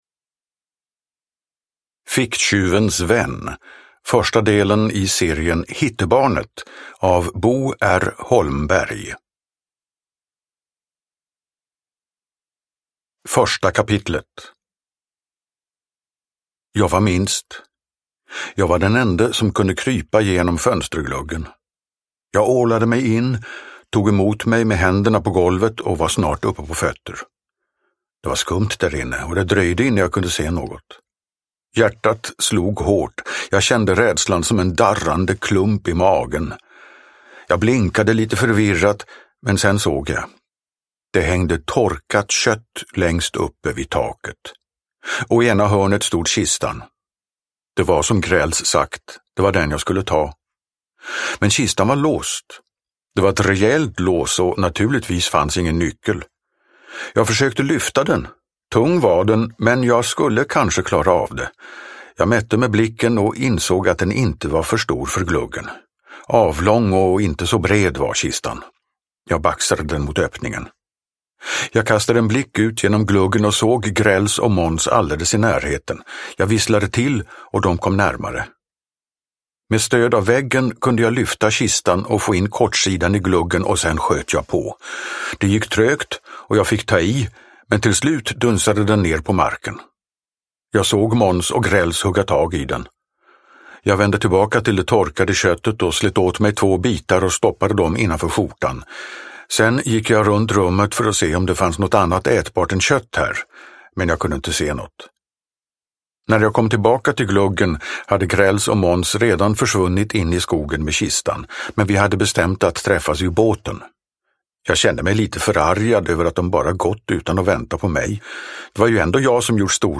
Ficktjuvens vän – Ljudbok – Laddas ner